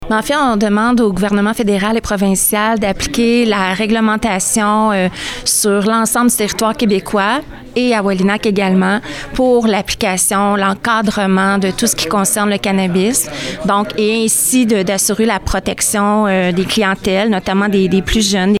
Si les élus sont inquiets des répercussions possibles sur la population, la mairesse, Lucie Allard, a rappelé qu’il y a une réglementation qu’il faut respecter quant à ces produits.